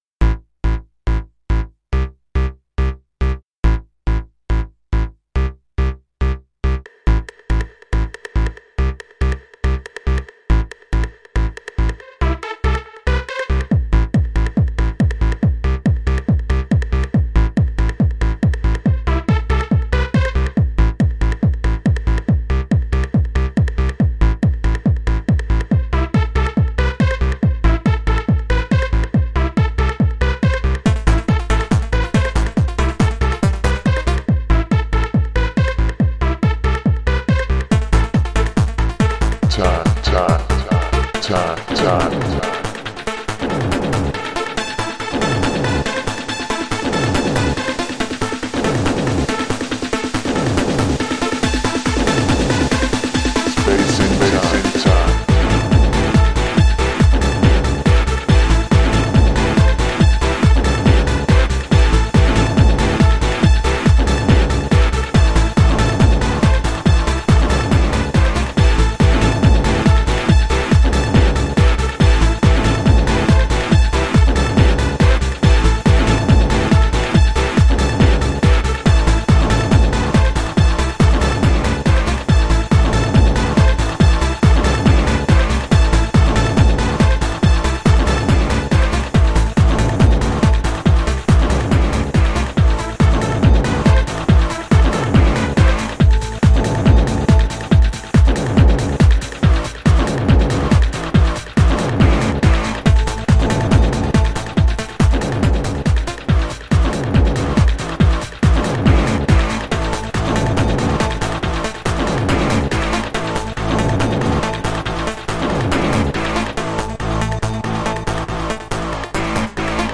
der wieder sehr kreative und voller Energie ist.
Eine sehr knackige und treibende Bassline
und viele neue Soundelemente